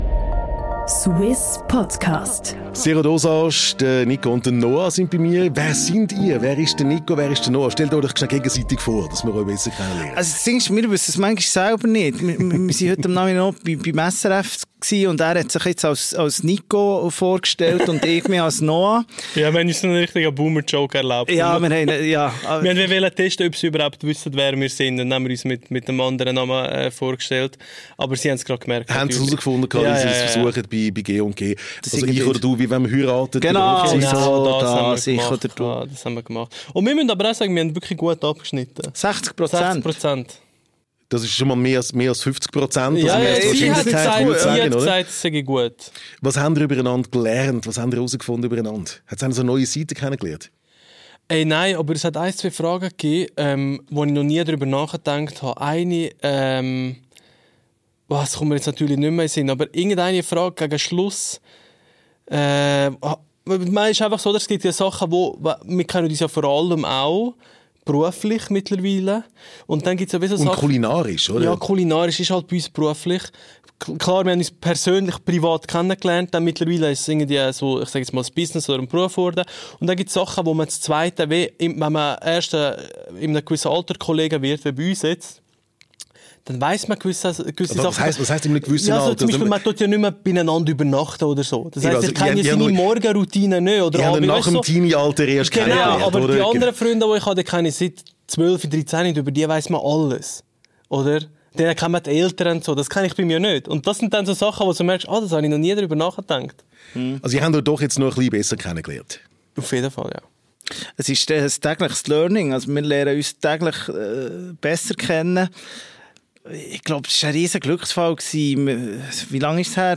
tauchen wir in ein unterhaltsames Gespräche ein, das Herz und Magen gleichermaßen berühren.